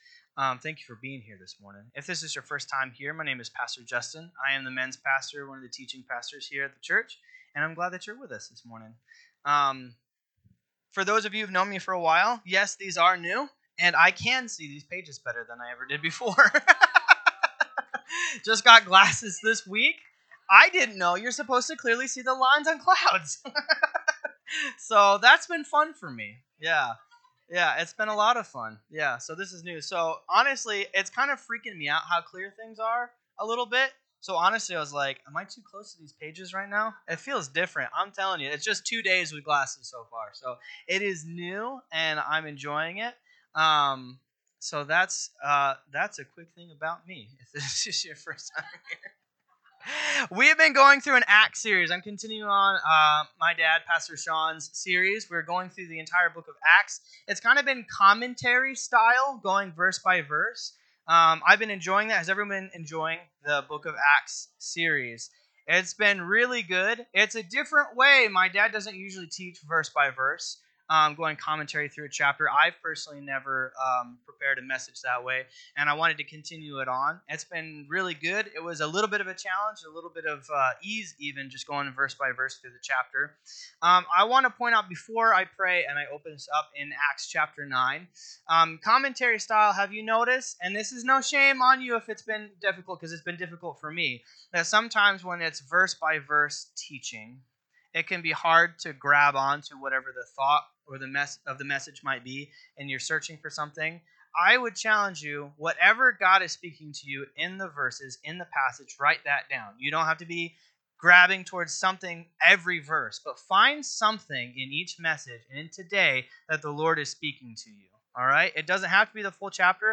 NSCF Sermons Online Acts of the Apostles Chapter 9 Sep 09 2025 | 00:47:58 1x 00:00 / 00:47:58 Subscribe Share RSS Feed Share Link Embed